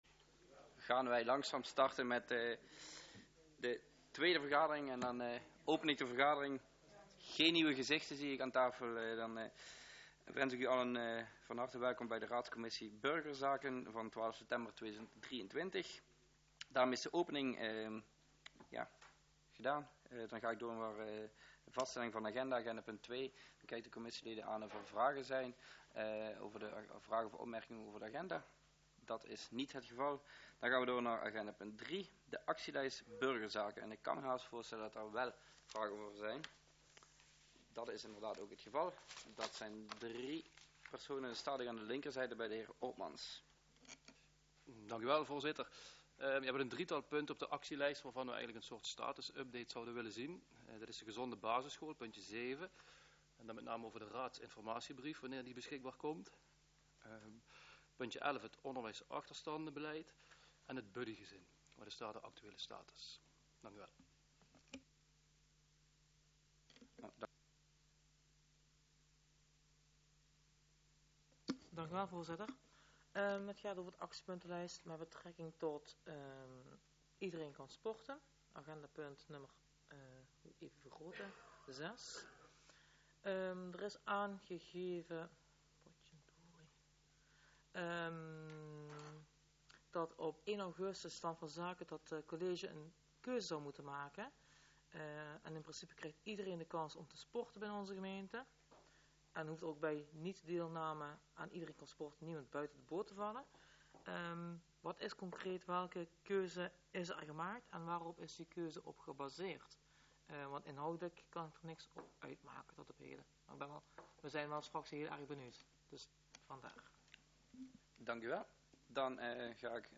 Locatie Raadszaal Voorzitter De heer R. Kisters Toelichting Deze vergadering begint aansluitend aan de vergadering van de Raadscommissie MID die om 19.00 uur begint.